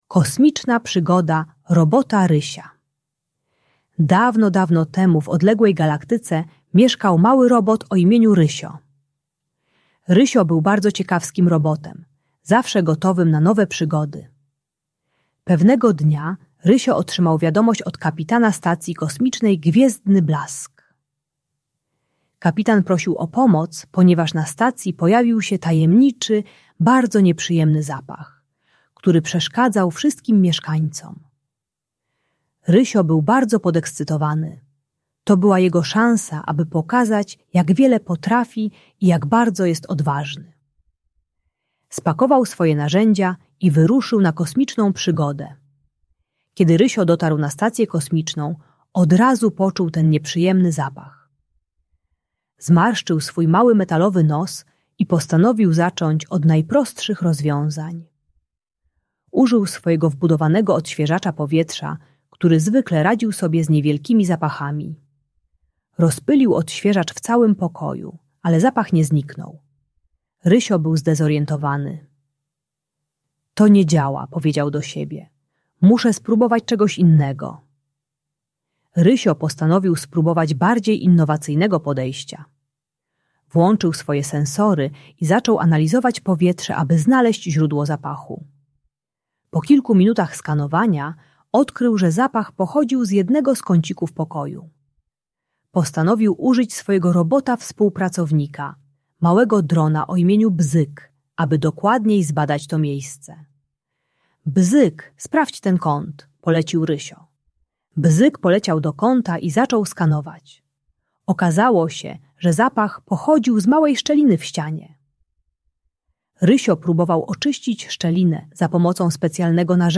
Kosmiczna Przygoda Robota Rysia - Trening czystości | Audiobajka